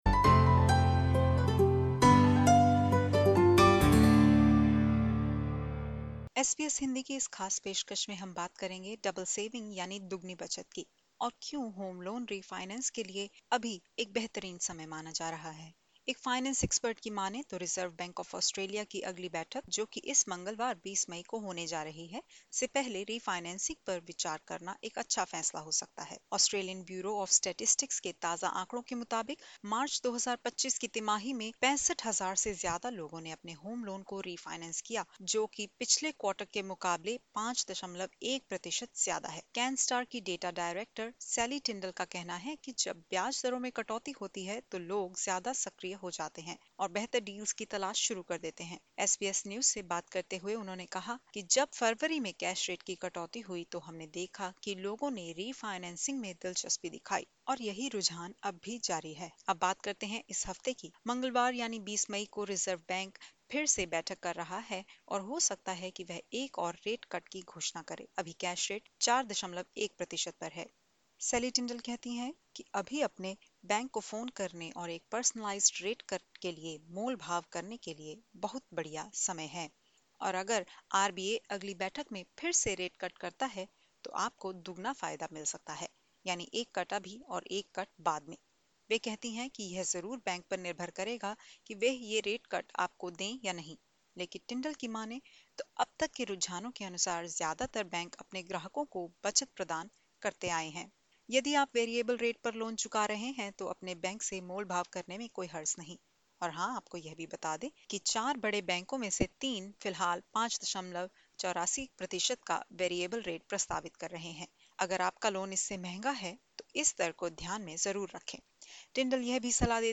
Disclaimer: The information given in this interview is of general nature.